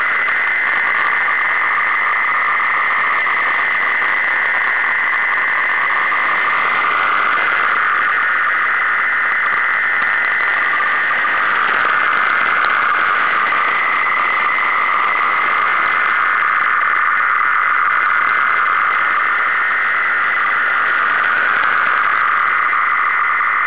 8082 signal
4_ray_fsk.wav